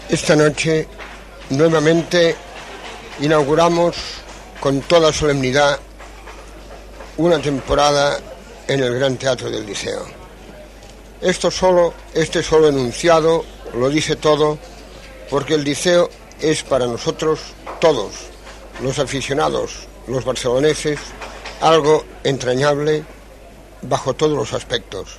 Transmissió des del Gran Teatre del Liceu de Barcelona. Comentari sobre l'inici de la temporada operística